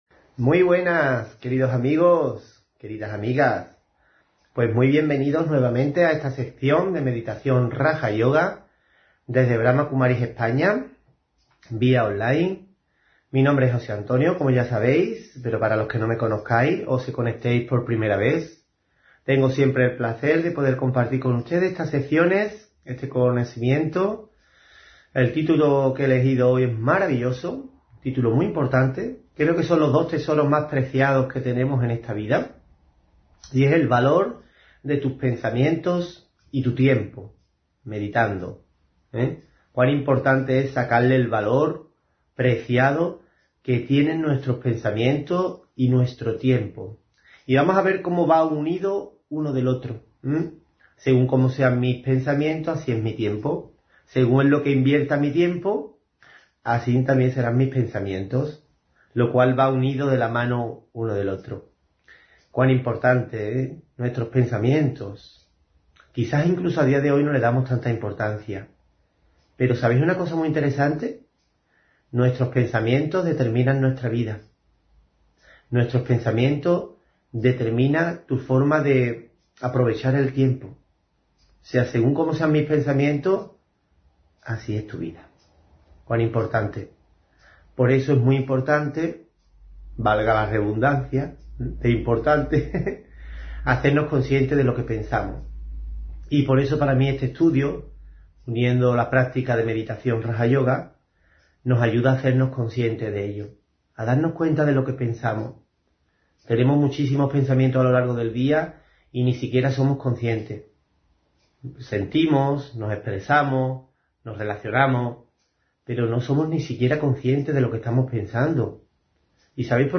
Meditación y conferencia: El valor de tus pensamientos y tu tiempo meditando (6 Noviembre 2024)